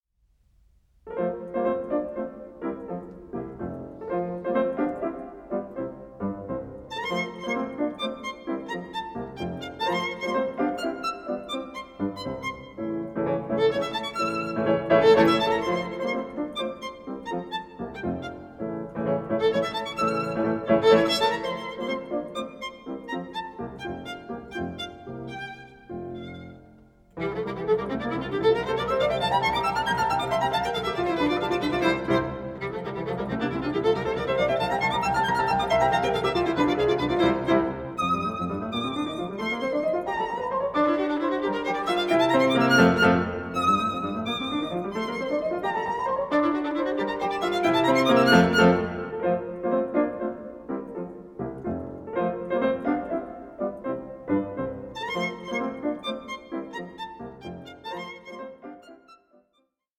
newly remastered multi-channel